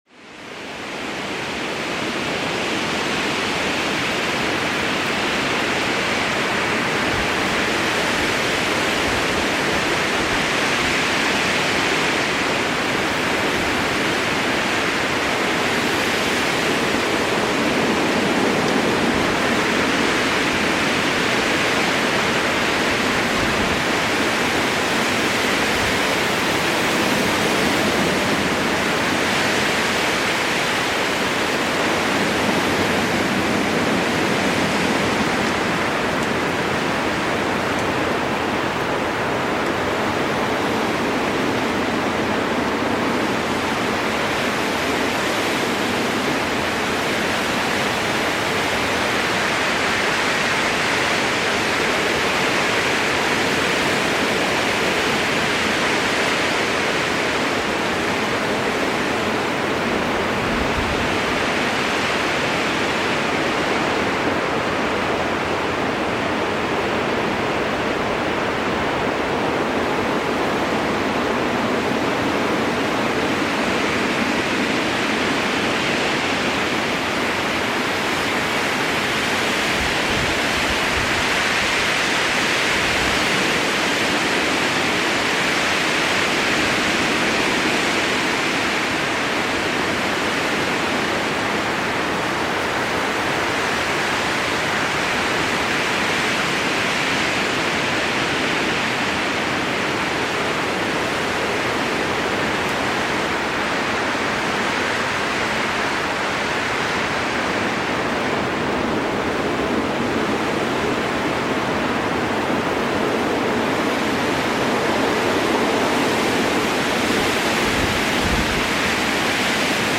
FICHTEN-GESANG: Abendwind-Ritual singt Sie in den Schlaf